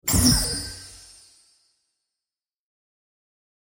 دانلود صدای اعلان خطر 5 از ساعد نیوز با لینک مستقیم و کیفیت بالا
جلوه های صوتی